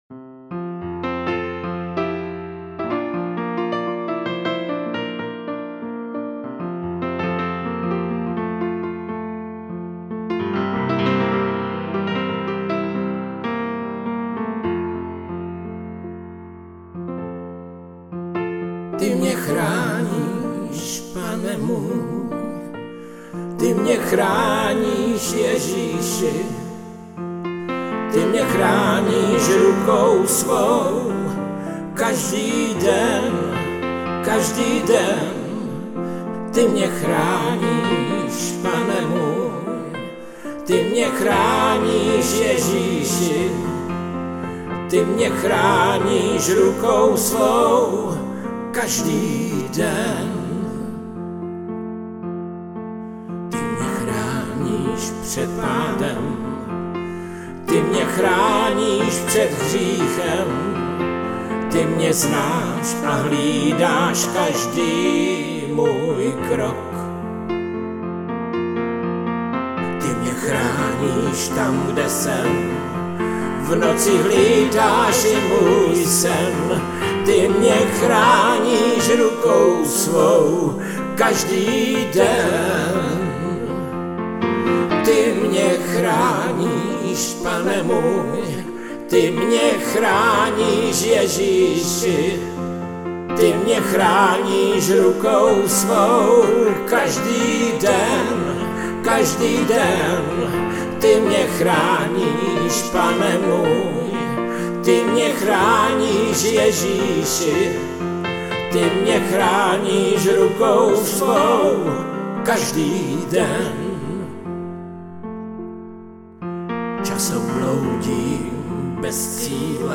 Křesťanské písně